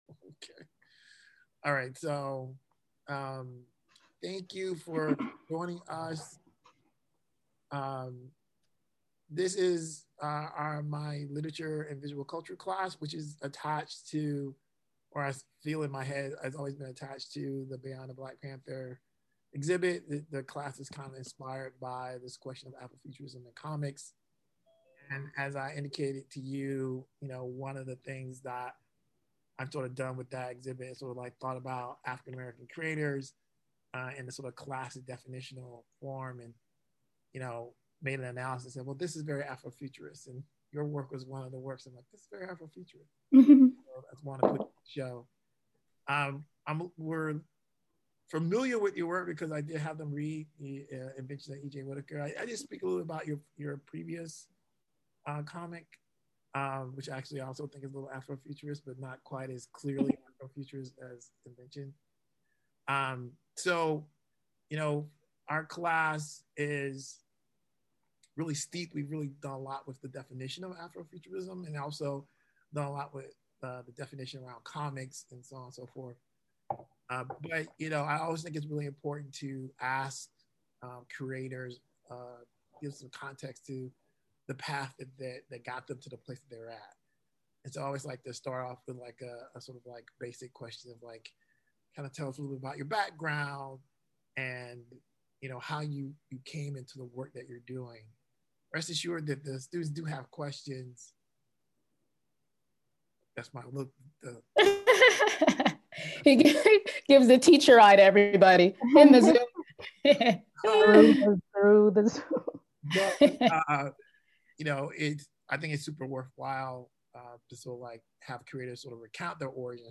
Interview
Material Type Interviews Sound recordings